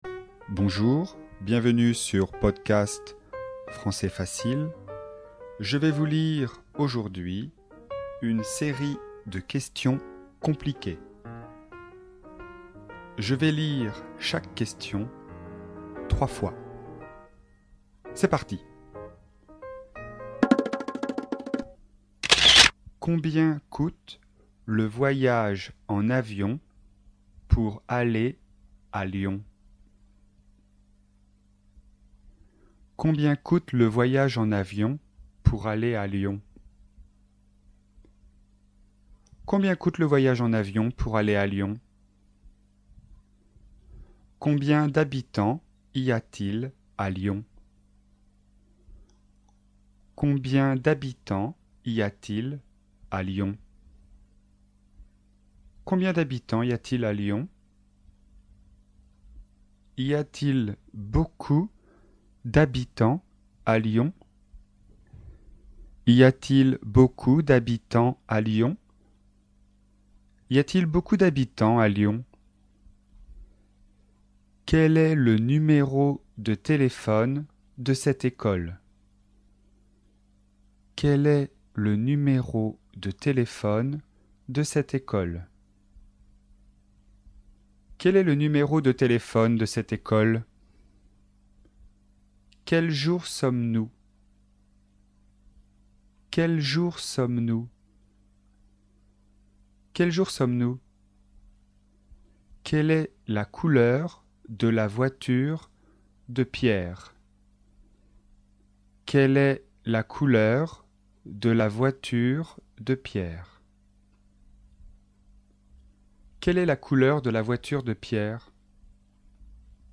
Phrases modèles, niveau débutant (A1), sur le thème de la forme interrogative.
Écoutez et répétez ces questions avec inversion du sujet.